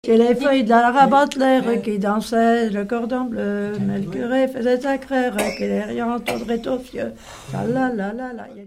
danse : gigouillette
Genre brève
Pièce musicale inédite